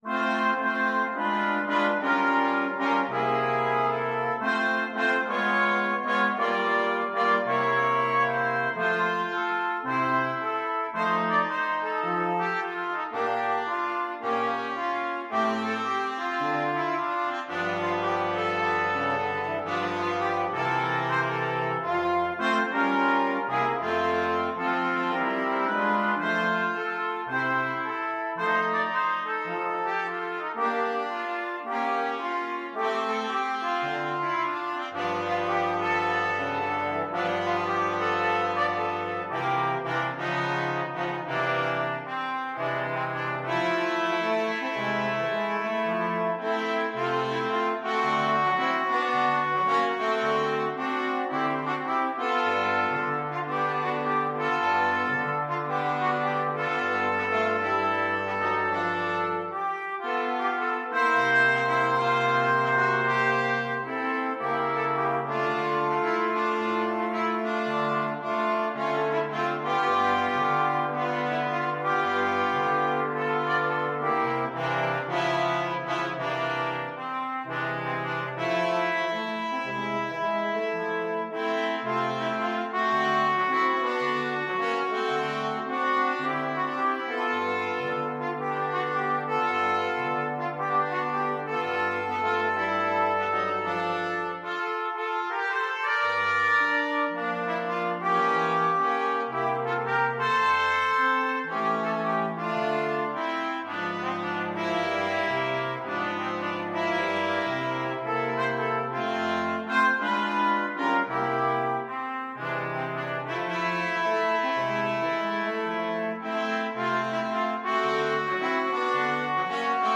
2/2 (View more 2/2 Music)
~ = 110 Moderate swing
Pop (View more Pop Brass Quartet Music)